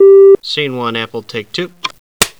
film take scene 1a take 2.wav